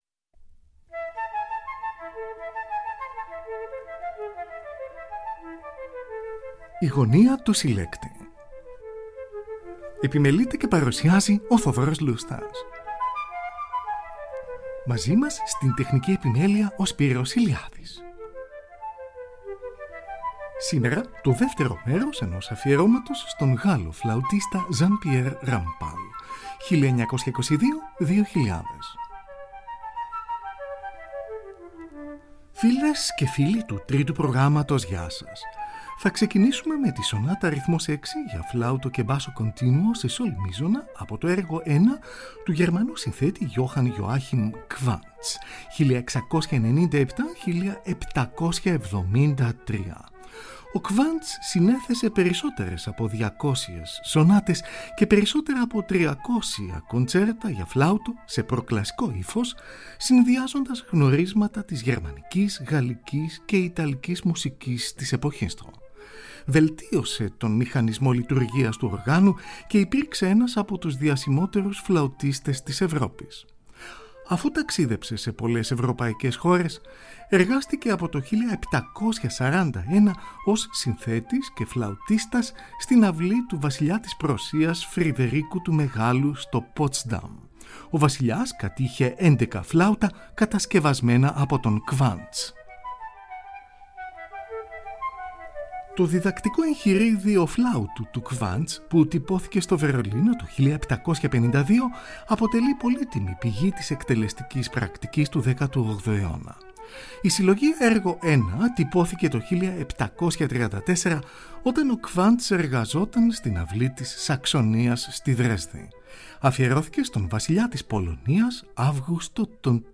Εργα για Φλαουτο